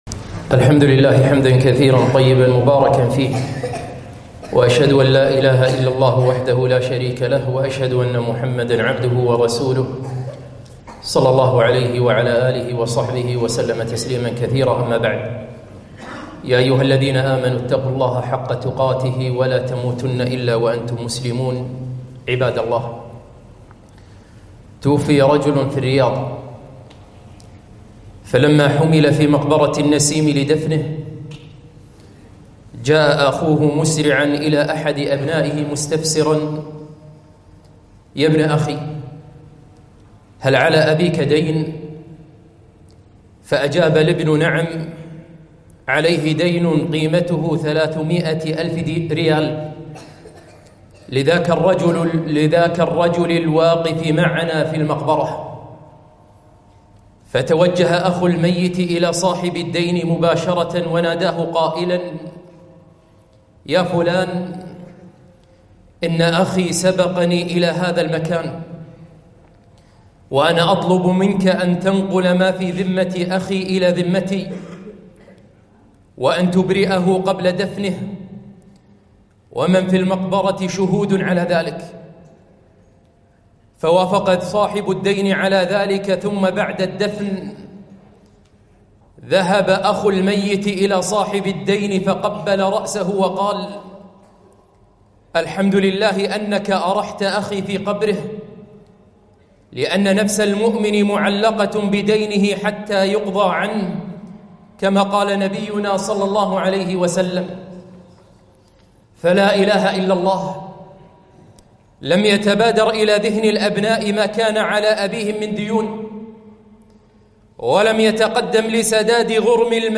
خطبة - أخوك سندك وظهرك